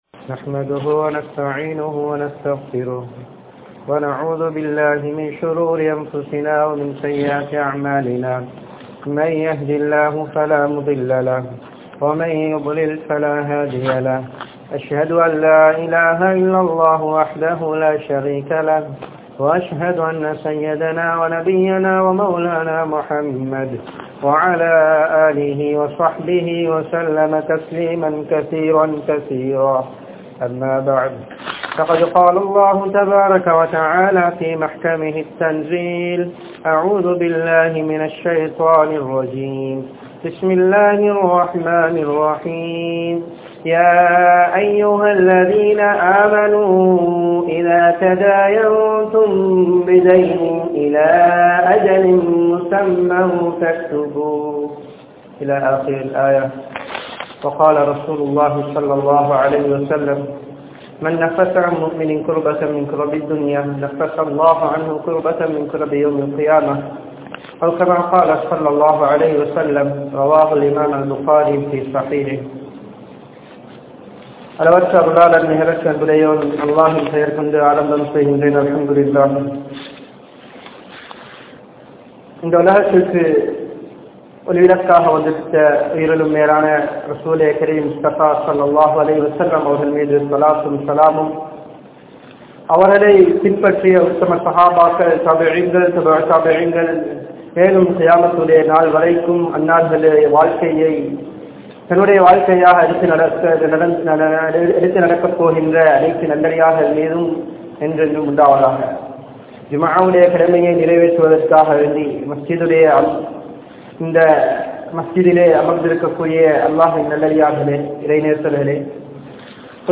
Islaaththtin Paarvaiel Kadan (இஸ்லாத்தின் பார்வையில் கடன்) | Audio Bayans | All Ceylon Muslim Youth Community | Addalaichenai
Masjidhul Hakam Jumua Masjidh